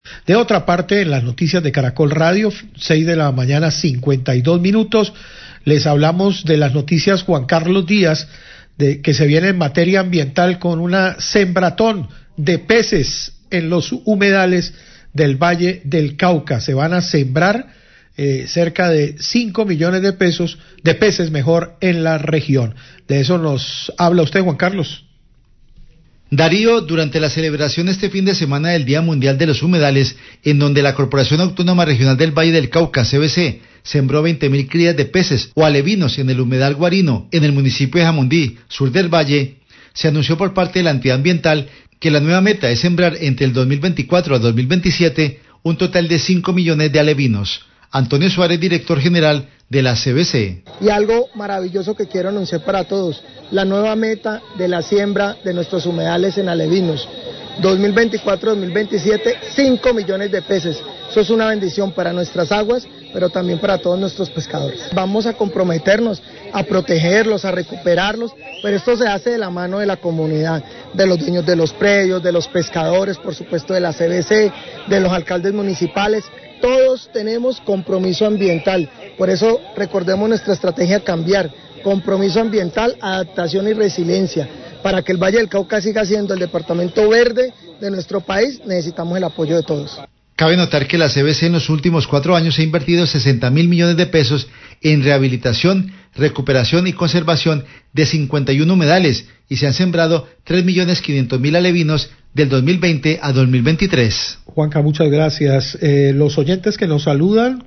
Radio
En el marco de la celebración del Día Mundial de los Humedales, la CVC sembró 20.000 alevinos en  el humedal Guarinó en Jamundí. El director general de la CVC, Marco Antonio Suárez, habla de la meta de sembrar 5 millones de peces con el compromiso ambiental de comunidades y autoridades municipales.